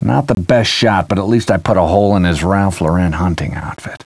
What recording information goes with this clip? These Deerhunter Wavs Are From A Hunting CD Game Where Deer Hunt People That I Had Years Ago